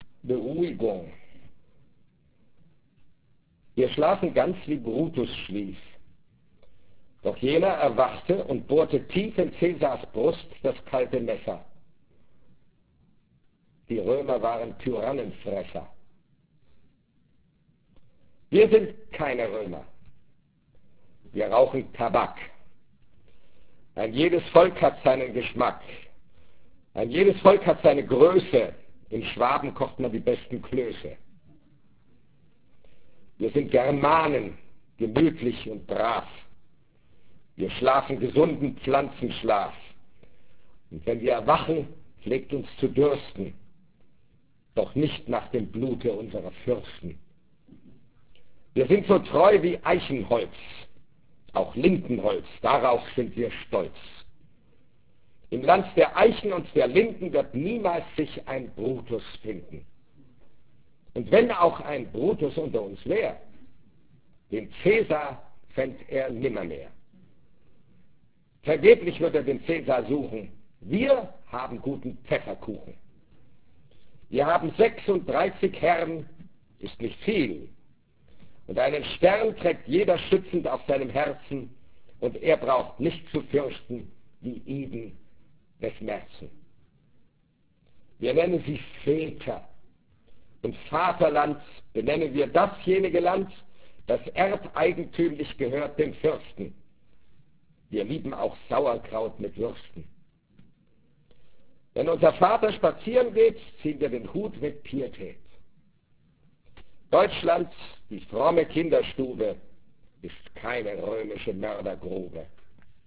Heinrich Heine Jahr Düsseldorf 1997 - Karlheinz Böhm liest Heinrich Heine - Aufzeichnungen vom 1. Dezember 1996 im Heinrich-Heine-Institut Düsseldorf